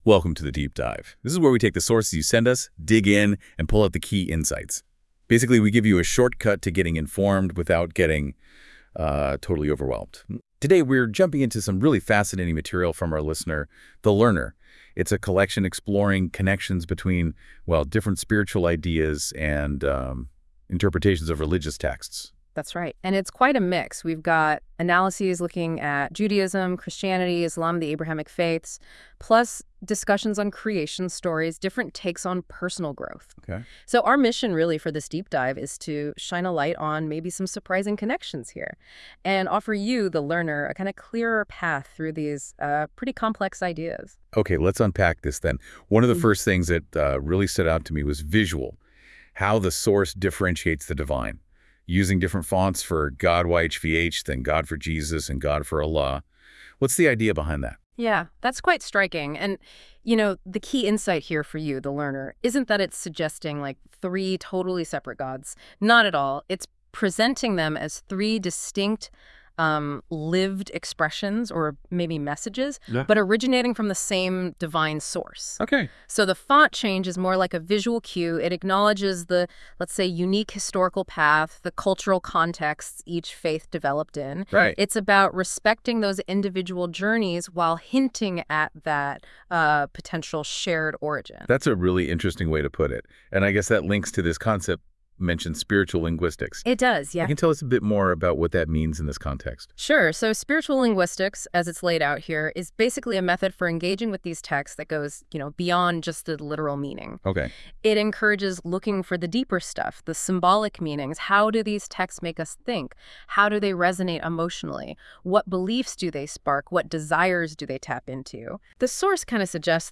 AI Audio Interview